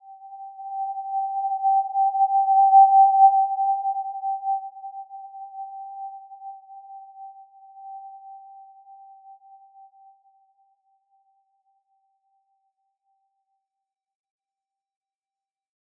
Simple-Glow-G5-mf.wav